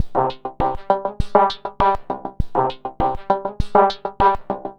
tx_synth_100_augment_CGAb1.wav